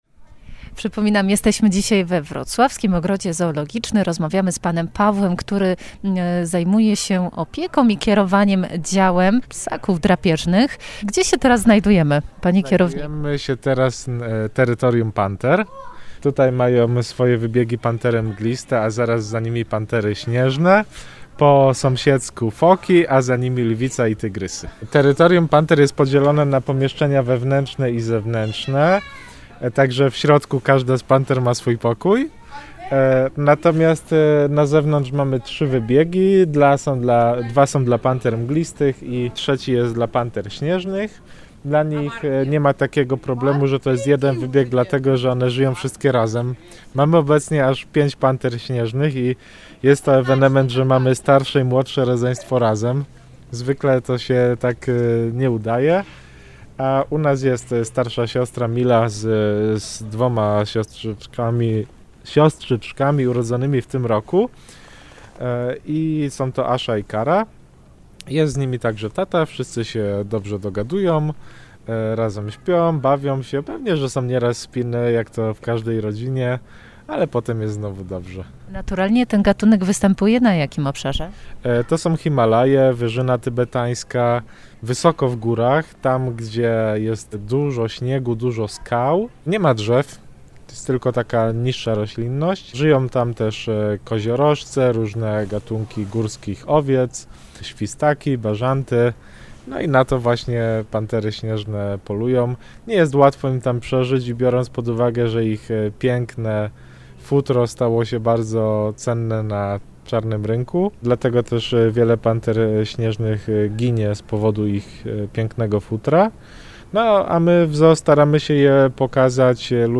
Sprawdzimy to w ZOO Ogrodzie Zoologicznym we Wrocławiu.